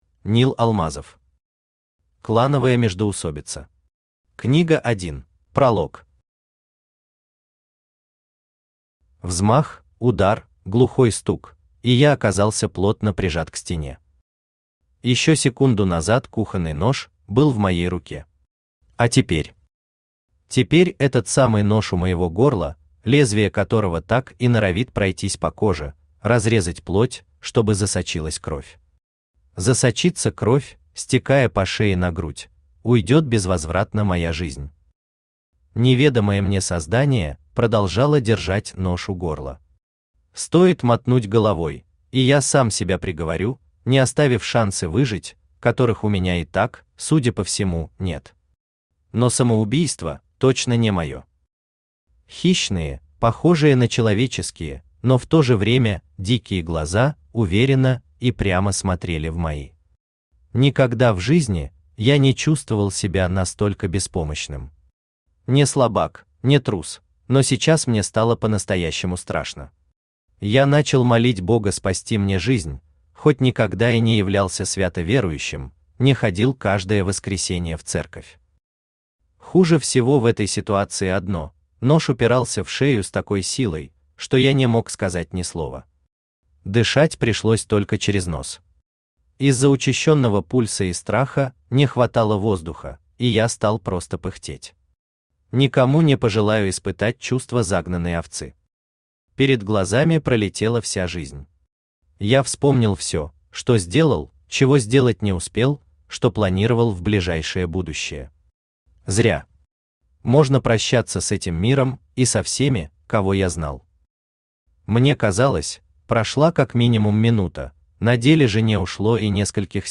Книга 1 Автор Нил Алмазов Читает аудиокнигу Авточтец ЛитРес.